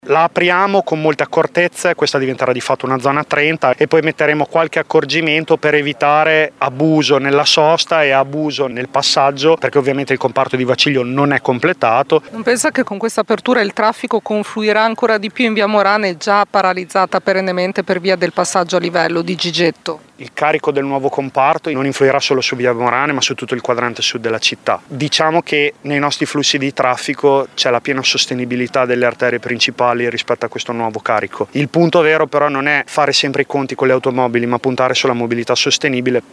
Ma sentiamo l’assessore ai lavori pubblici Giulio Guerzoni: